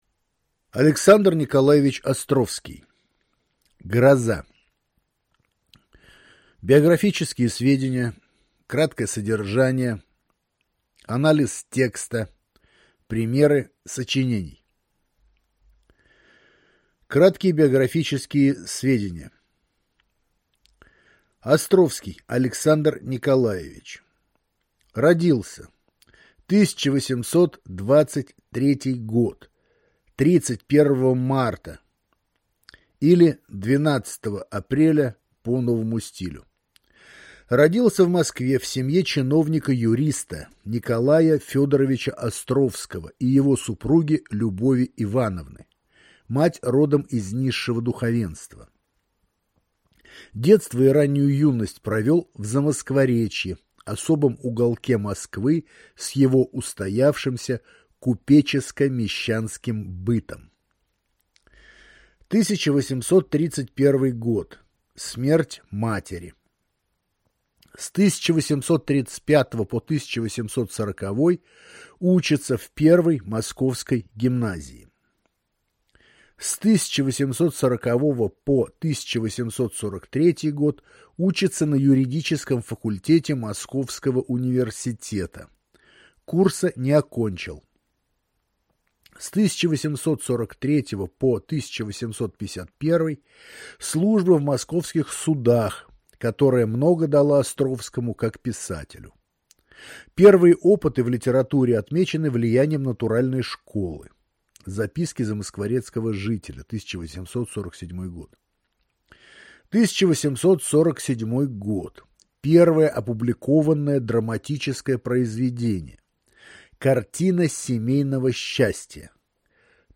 Аудиокнига А. Н. Островский «Гроза».